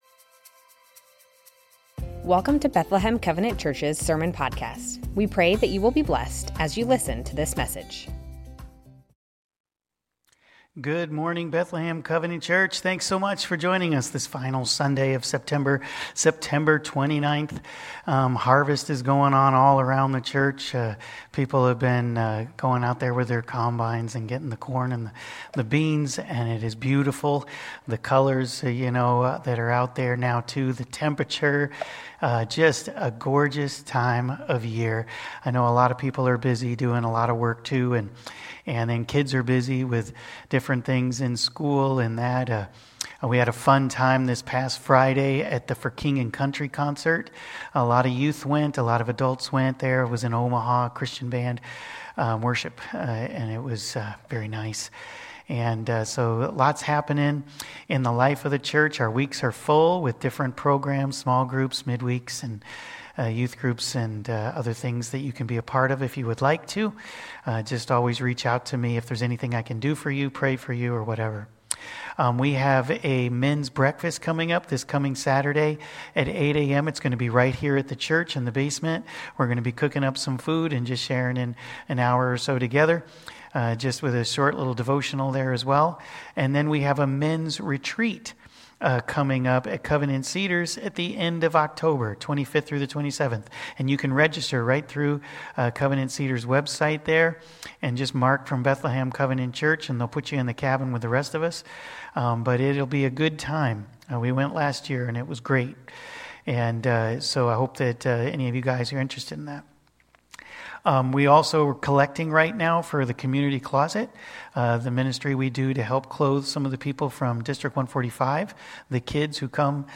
Bethlehem Covenant Church Sermons Living to please God - 1 Thessalonians 5:1-11 Sep 29 2024 | 00:33:02 1x 00:00 / 00:33:02 Subscribe Share Spotify RSS Feed Share Link Embed